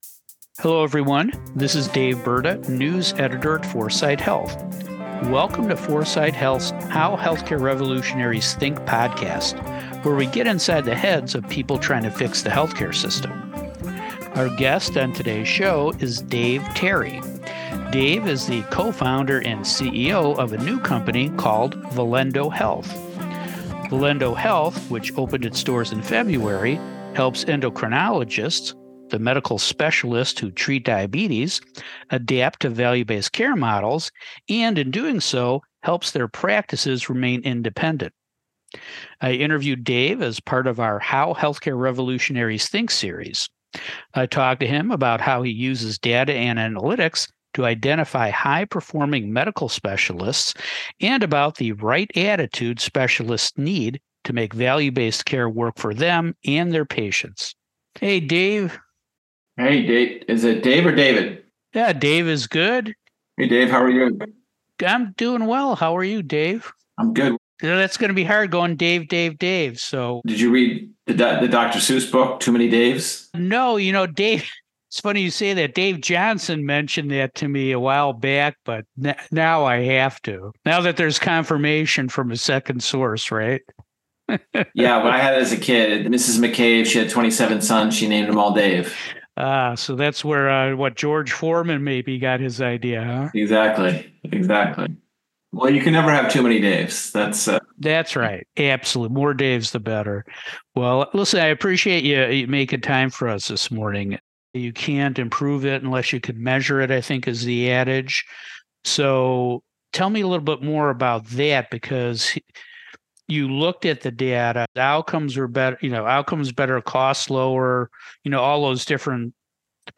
But it takes spending time with them to find out if they have the right attitude to make value-based care work for them and their patients. Enjoy this podcast interview with 4sight Health’s latest healthcare revolutionary.